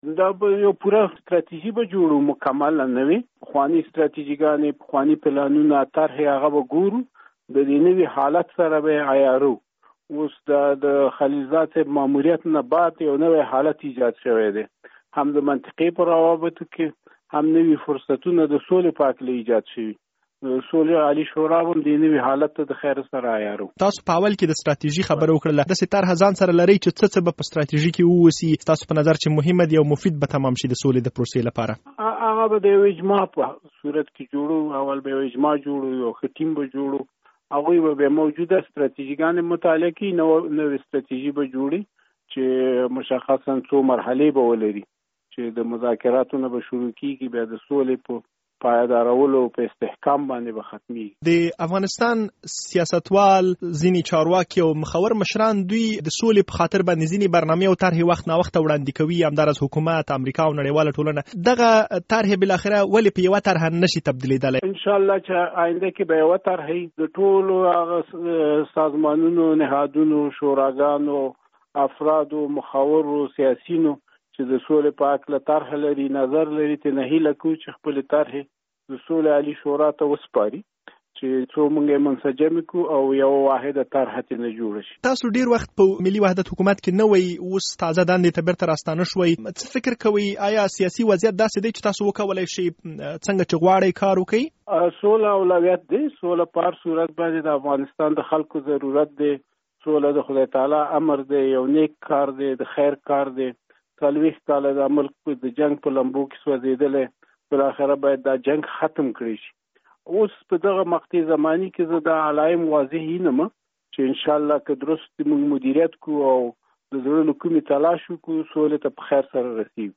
مرکه
له ښاغلي داوودزي سره د ازادي راډیو خبریال مرکه کړې او په سر کې یې پوښتنه کړې چې د سولې په برخه کې به موجوده هڅې څنګه سره یوځای کړي.
له ښاغلي داوودزي سره مرکه